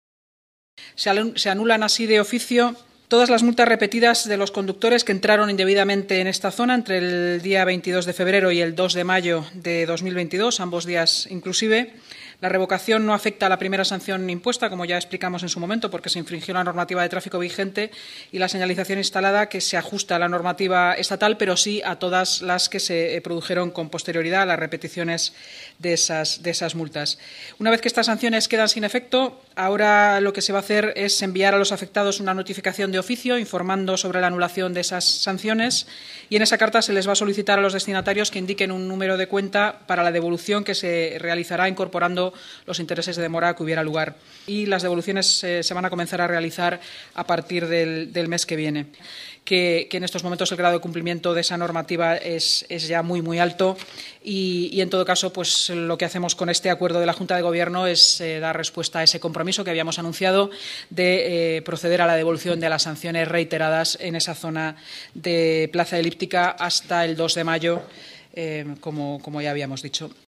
Nueva ventana:Inmaculada Sanz, portavoz municipal